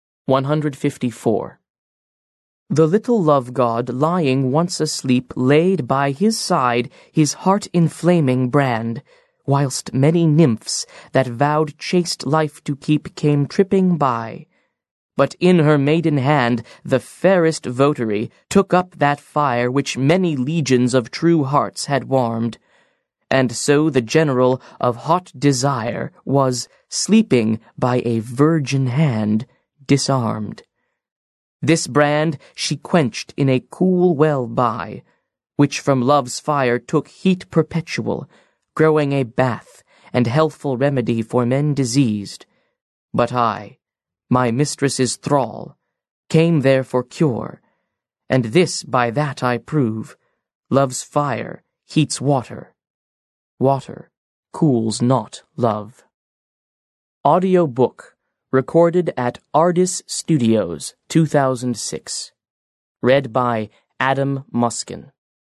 Аудиокнига The Sonnets | Библиотека аудиокниг